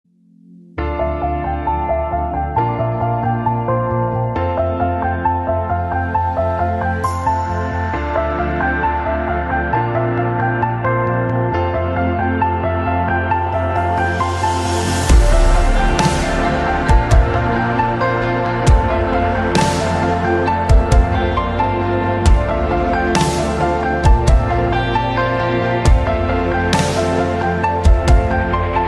Kategorien Klassische